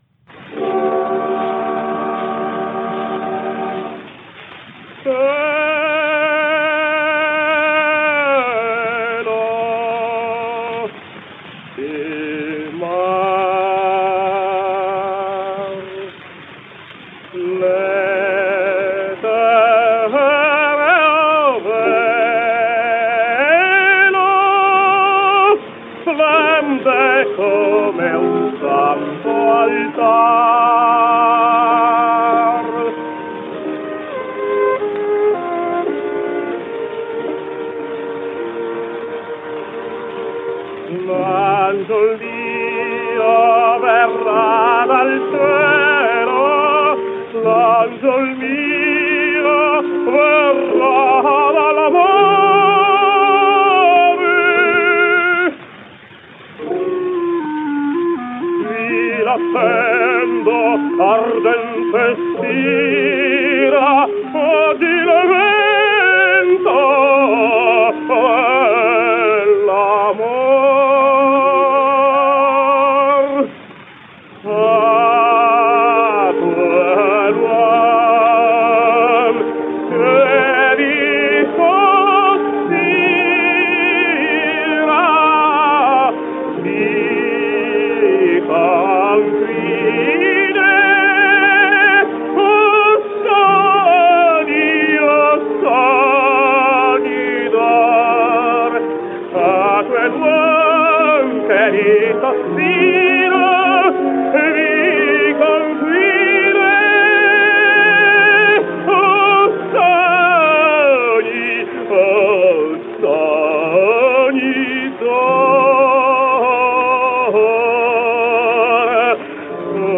Giovanni Zenatello | Italian Tenor | 1876 - 1949 | Tenor History